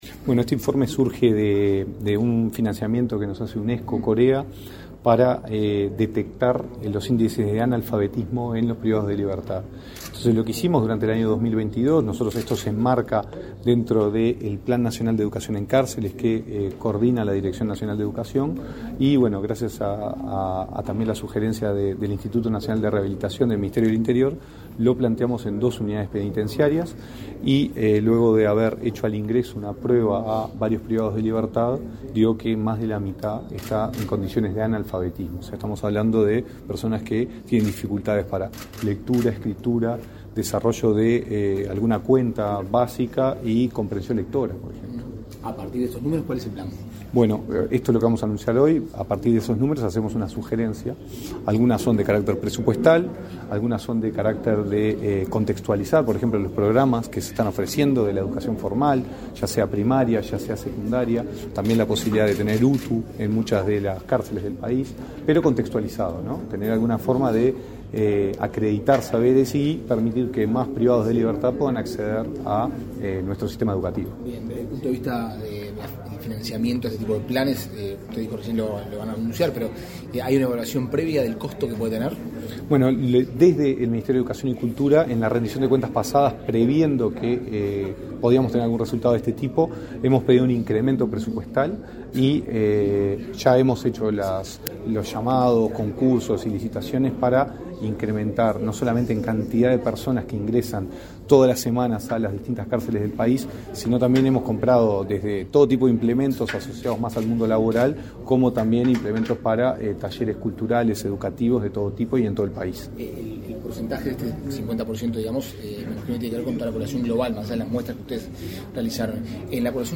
Declaraciones a la prensa del director nacional de Educación, Gonzalo Baroni
Declaraciones a la prensa del director nacional de Educación, Gonzalo Baroni 30/03/2023 Compartir Facebook X Copiar enlace WhatsApp LinkedIn El Ministerio de Educación y Cultura (MEC) presentó, este 30 de marzo, el informe de resultados del modelo dispositivo de detección temprana de analfabetismo para la población adulta privada de libertad. En la oportunidad, Baroni realizó declaraciones a la prensa.